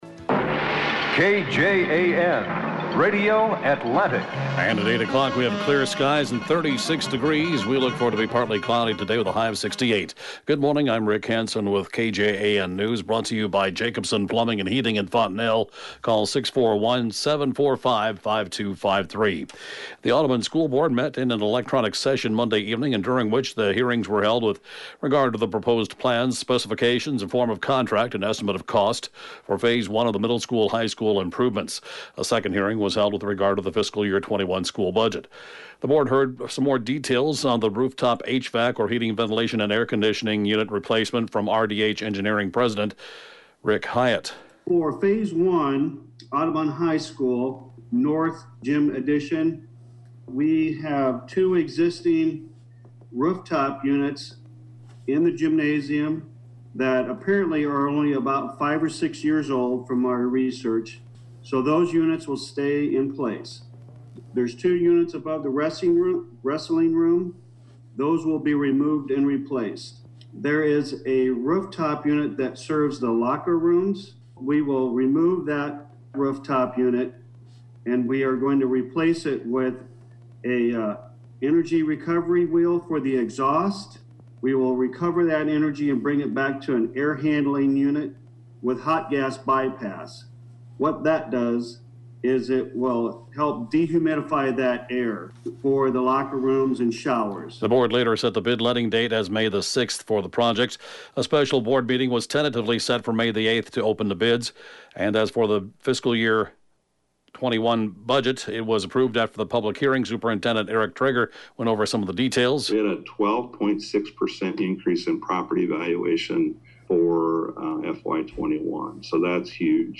(Podcast) KJAN 8-a.m. News, 4/21/20